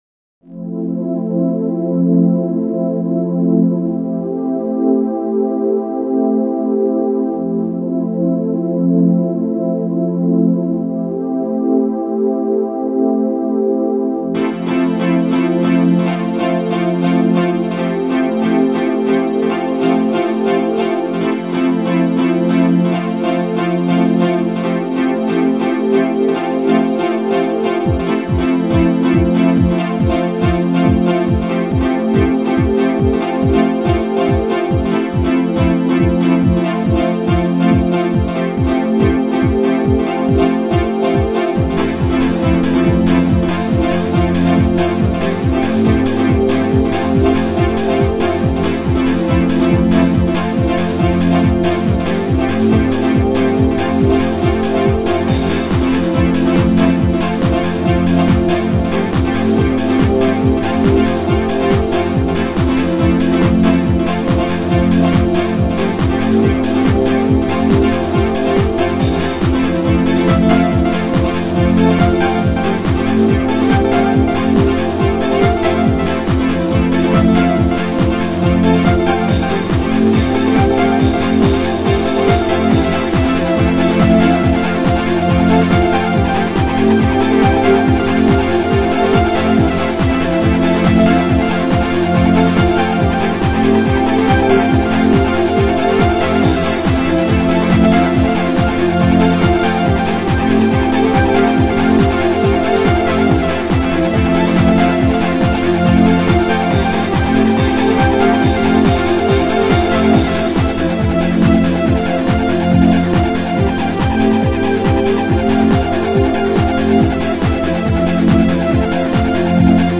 トランス
なんとなく当時の雰囲気が醸し出されています（若）   Roland VSC 3.2